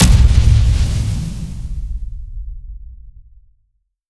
Destroyed.wav